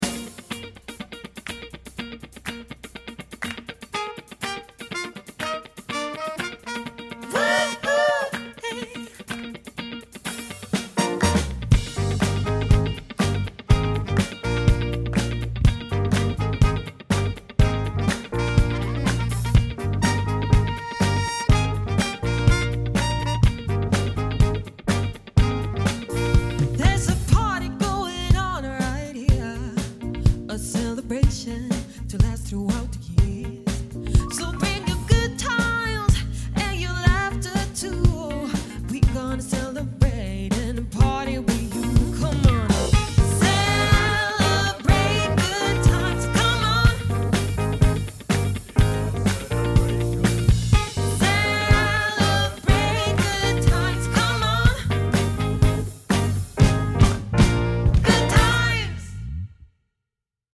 party/cover band
fem musiker